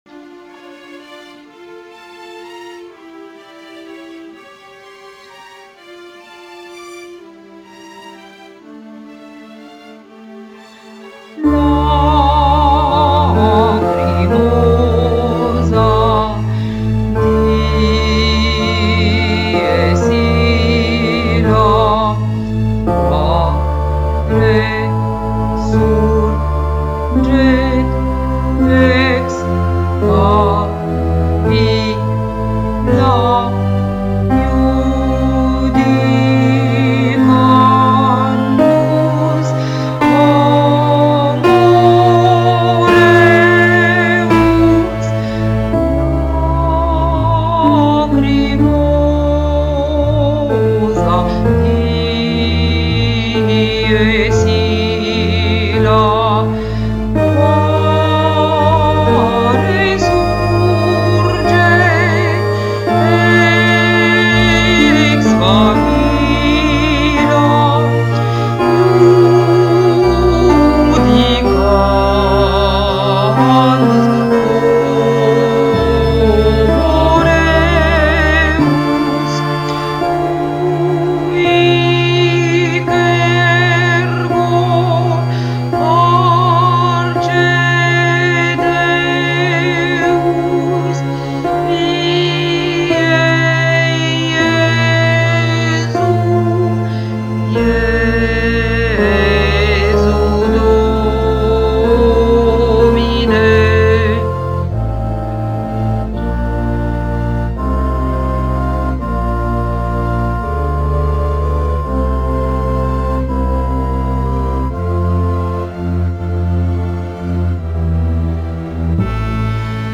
Tenor Mozart, Requiem, Lacrymosa, Tenor , Voice aid.mp3